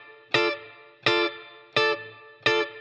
DD_TeleChop_85-Dmin.wav